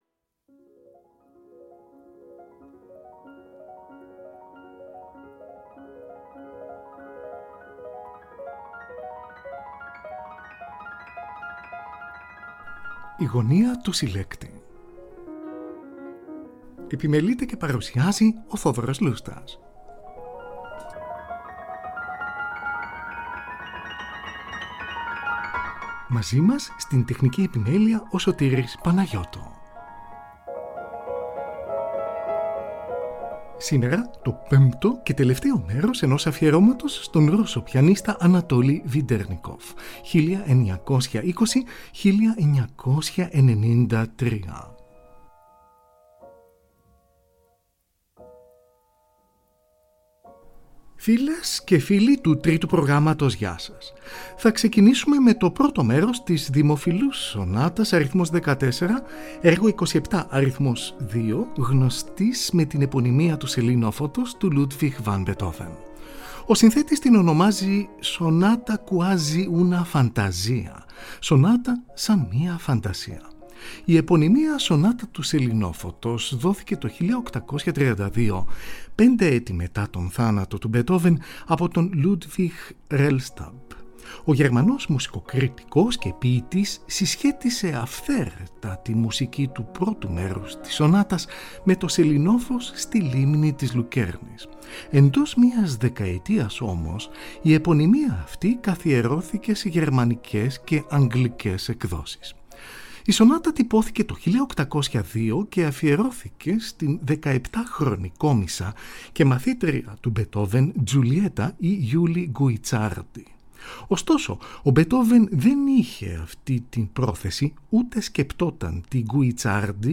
Εργα για Πιανο